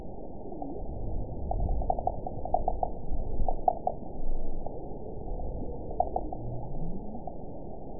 event 922081 date 12/26/24 time 06:48:57 GMT (11 months, 1 week ago) score 8.79 location TSS-AB03 detected by nrw target species NRW annotations +NRW Spectrogram: Frequency (kHz) vs. Time (s) audio not available .wav